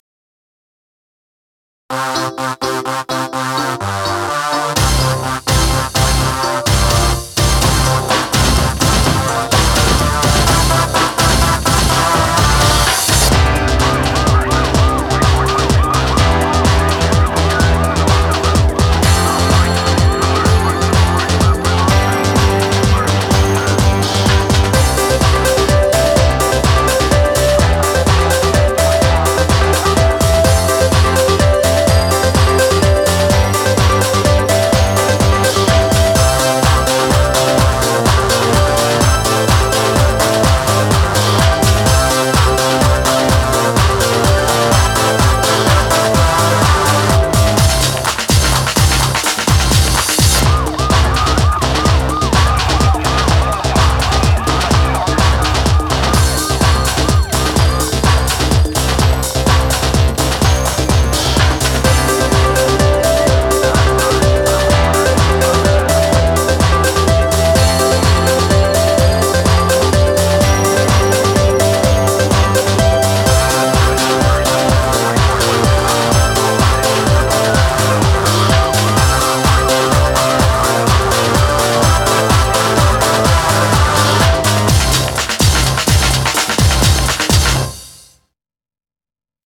BPM126
Audio QualityPerfect (High Quality)
Commentaires[HOUSE]